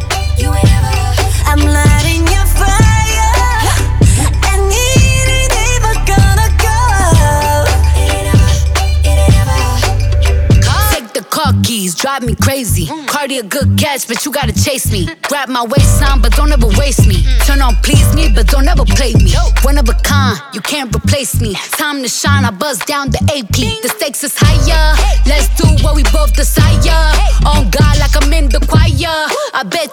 • K-Pop
South Korean girl group
American rapper